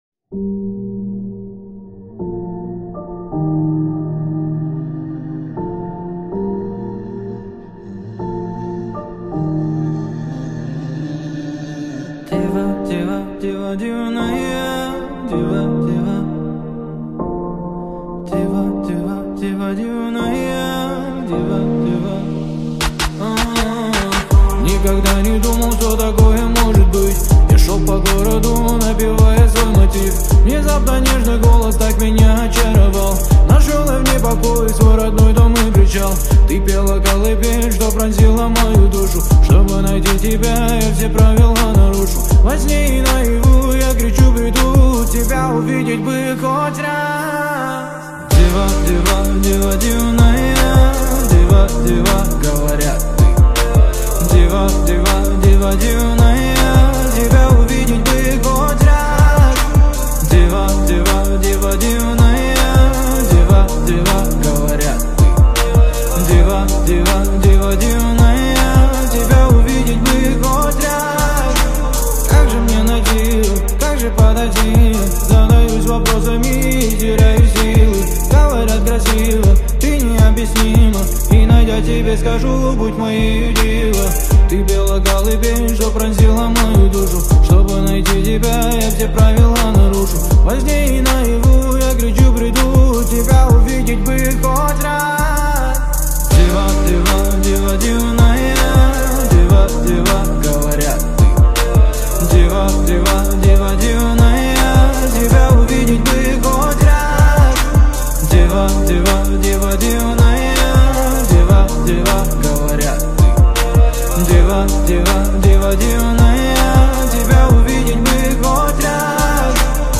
Русская музыка